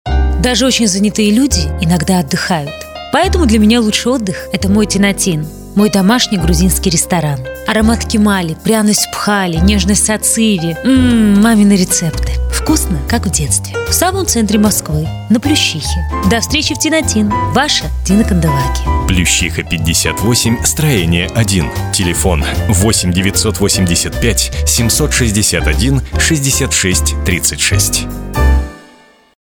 Что делали: реклама ресторана на радио.
Для записи рекламы ресторана мы приезжали в офис и писали Канделаки в самой что ни на есть рабочей обстановке.
Реклама ресторана «Тинатин» для радио